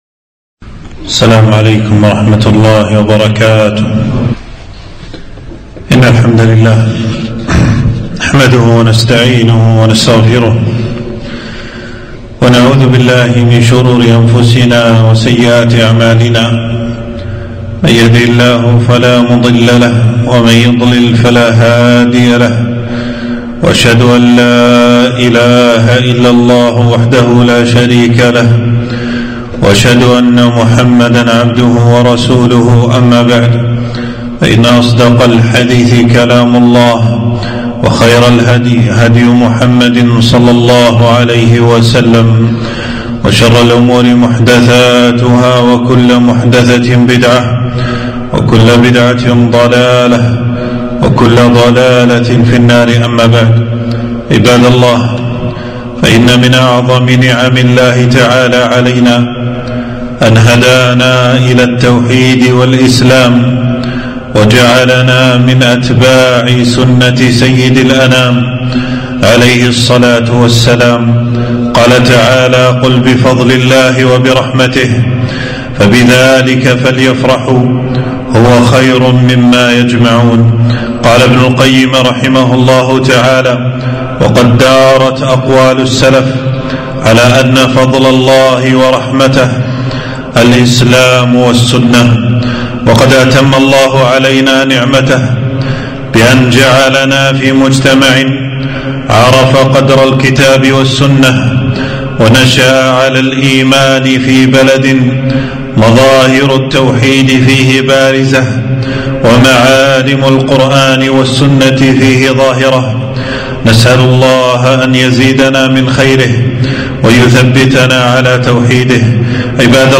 خطبة - مكائد أعداء الدين وحقيقة دعاة الحرية